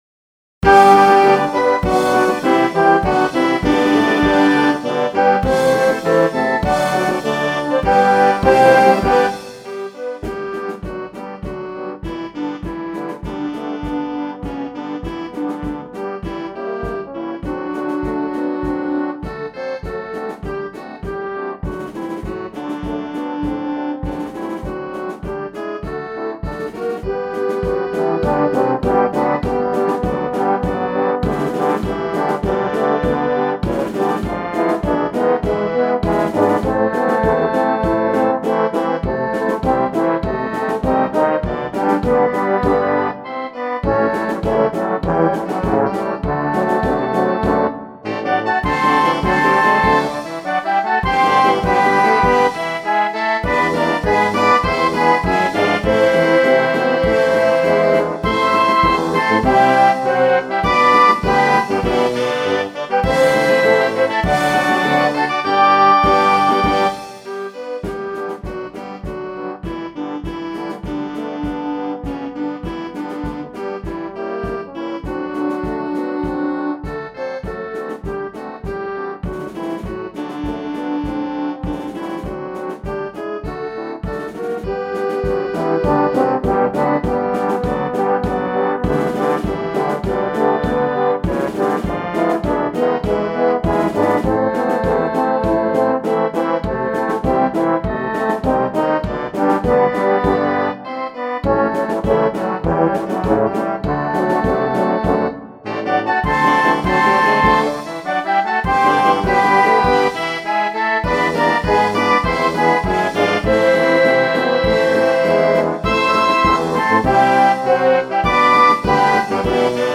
Baixar Hino Instrumental